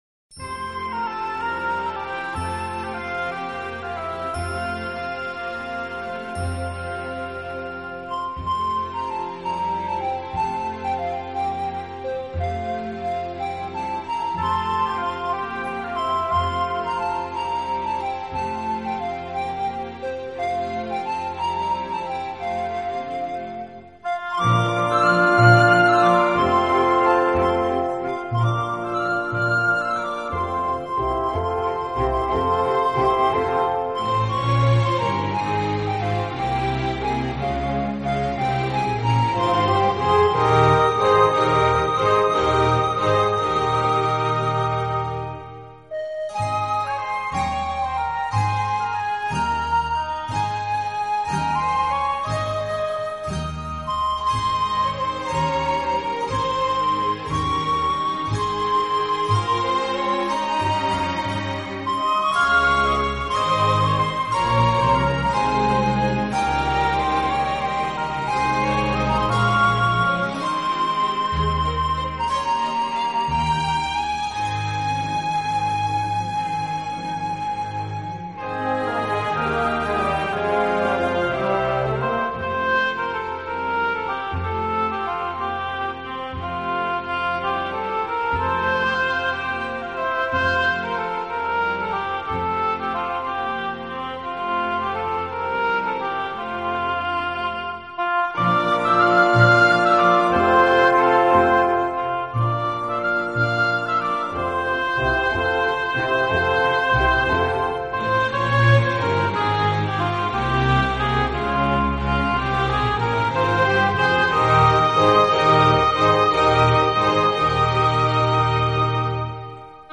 本专辑用长笛吹奏，长笛乐色清新、透彻，色调是冷的。